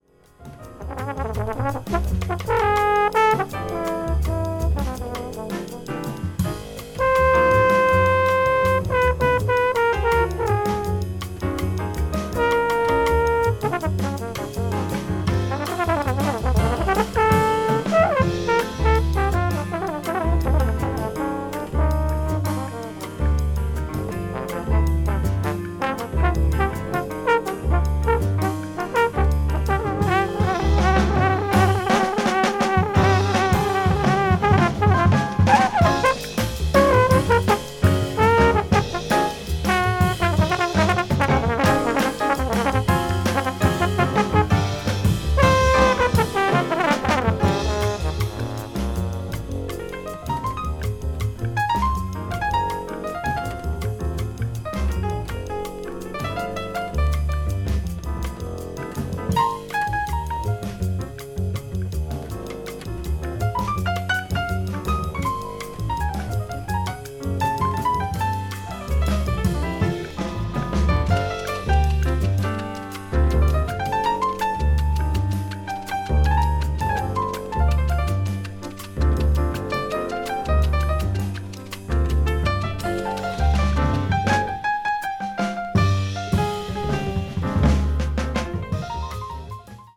contemporary jazz   post bop   spritual jazz